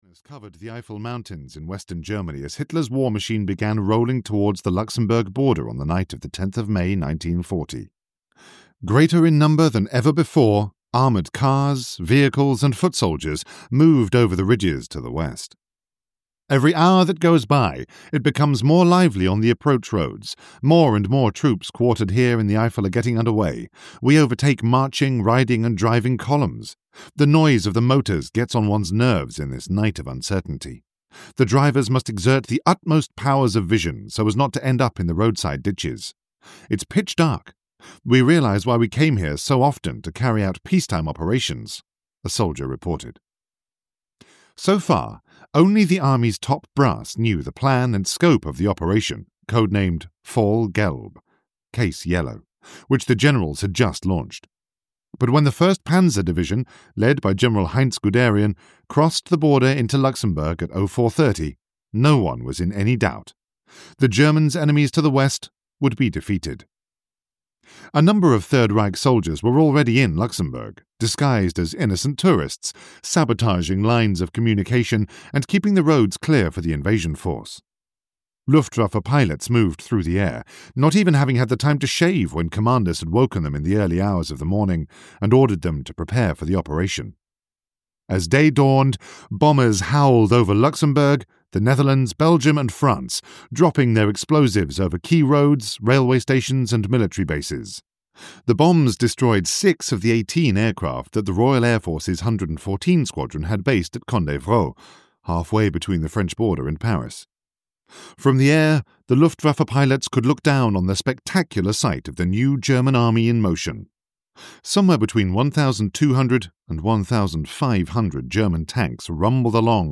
Battle of Britain (EN) audiokniha
Ukázka z knihy